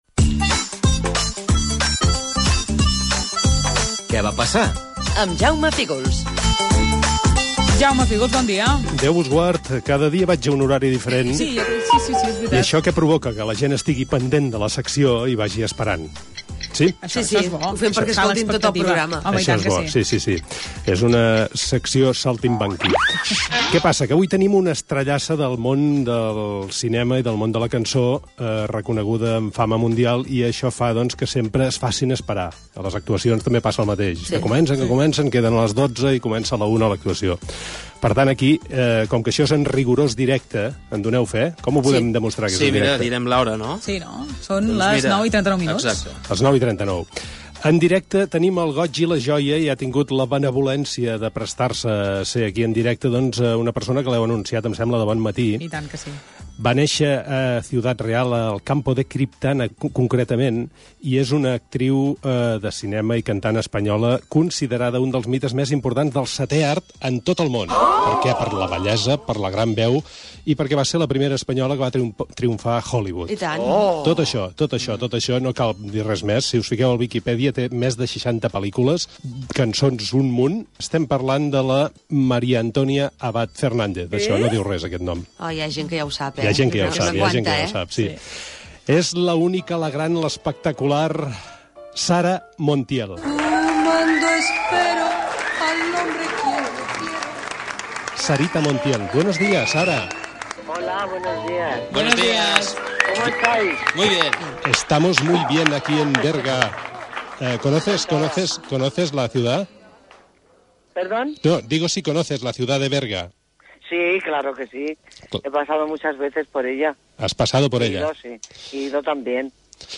Espai "Què va passar?". Careta del programa, hora i entrevista telefònica a l'actriu Sara Montiel (Maria Antonia Abad Fernández)
Entreteniment